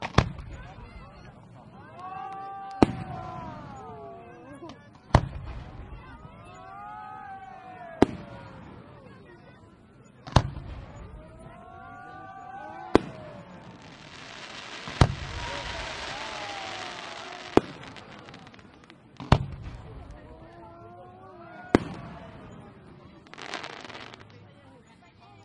焰火西尔维斯特 " 拍摄中
描述：切割的烟花
标签： 爆炸 烟花
声道立体声